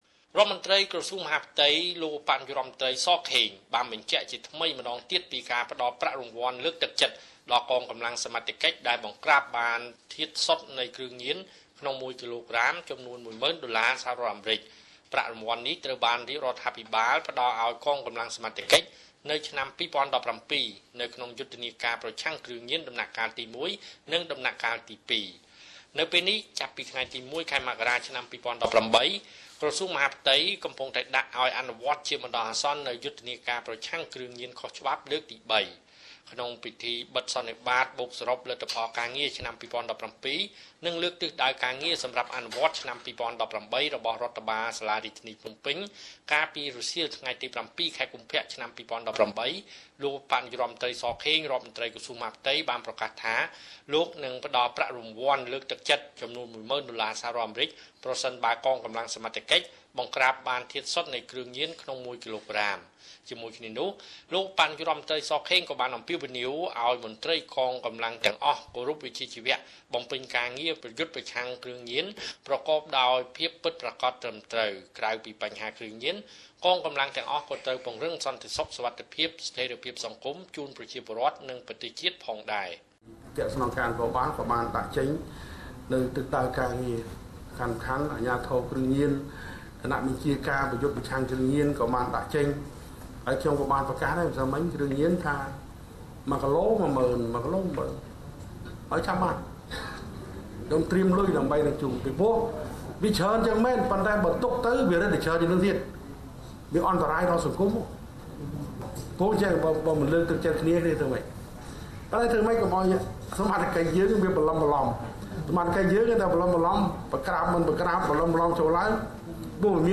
( សំឡេងលោកឧបនាយករដ្ឋមន្ត្រី ស ខេង )